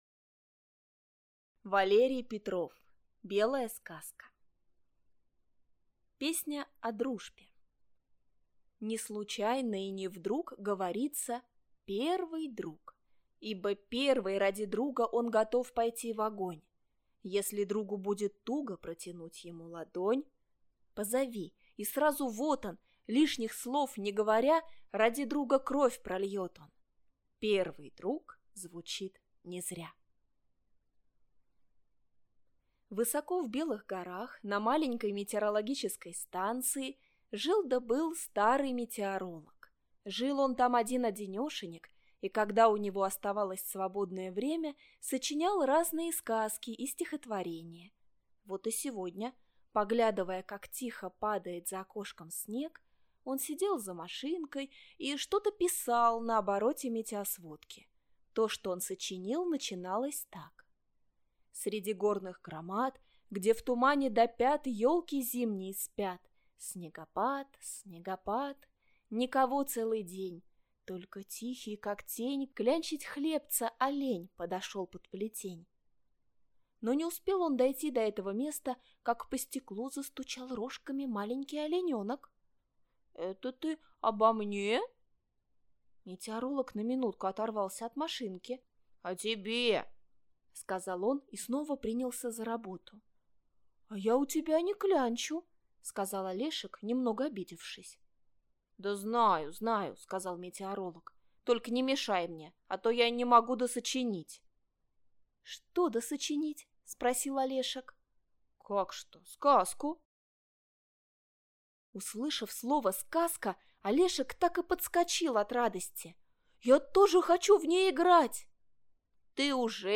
Белая сказка - аудиосказка